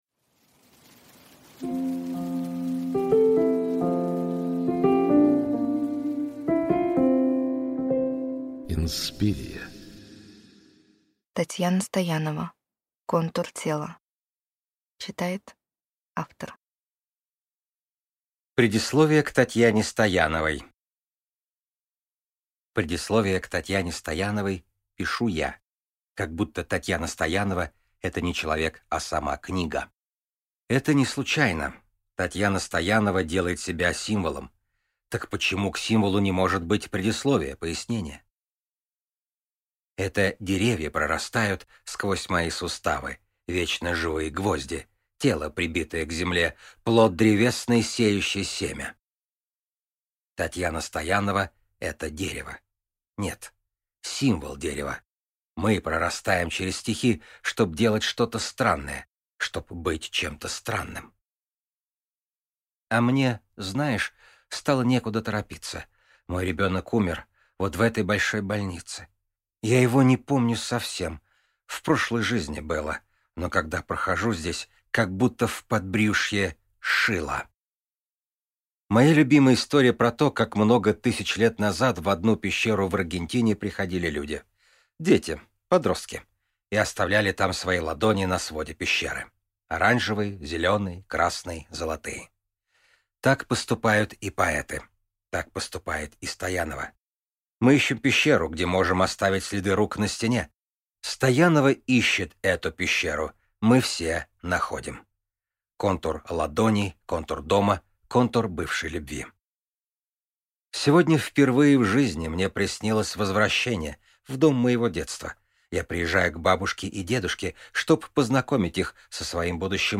Аудиокнига Контур тела | Библиотека аудиокниг